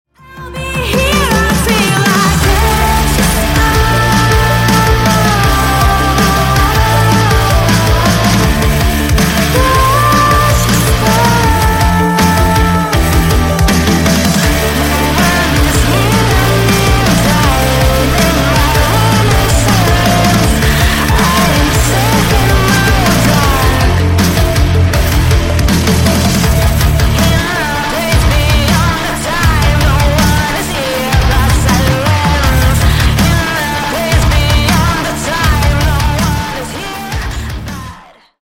Громкие Рингтоны С Басами
Рок Металл Рингтоны